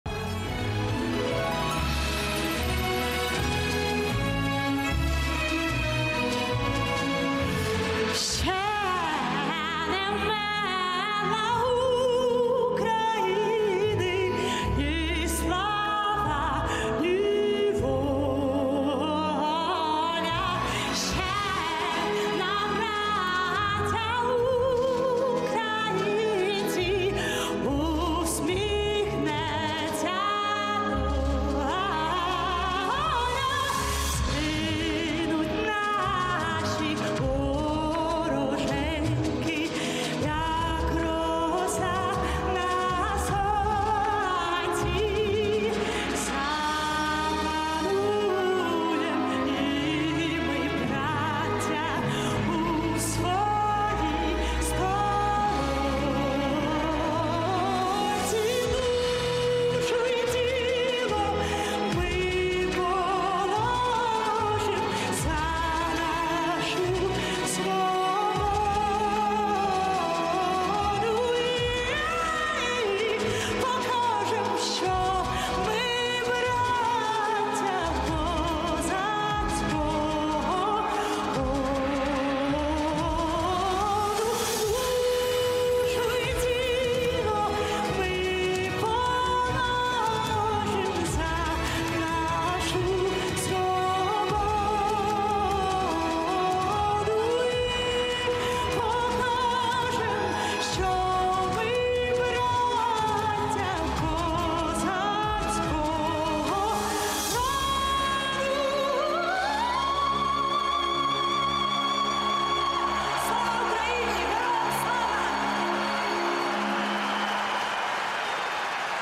• Качество: 320 kbps, Stereo
Благодійний матч